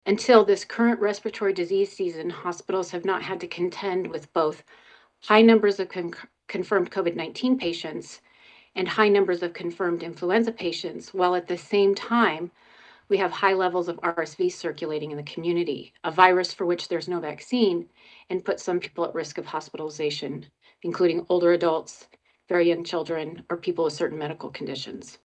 During a press availability, agency officials called the situation a ‘triple-demic’ of flu, respiratory syncytial virus (RSV), and COVID-19.